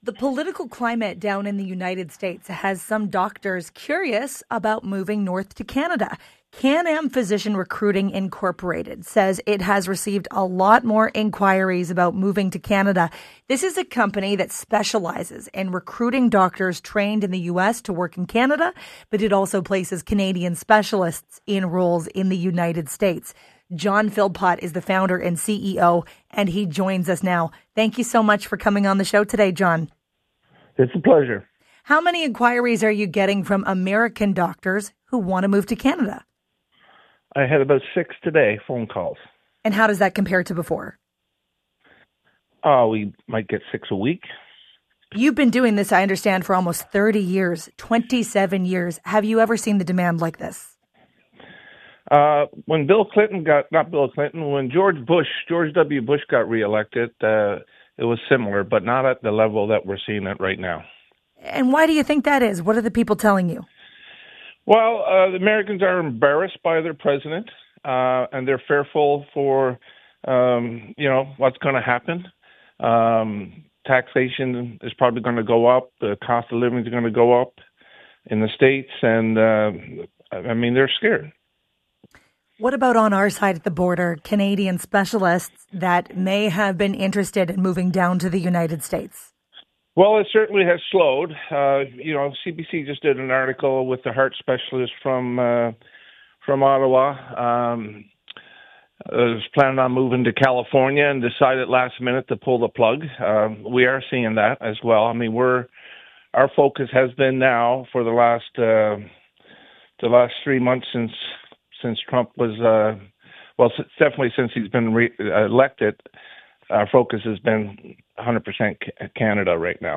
580-cfra Listen to the full interview to learn more https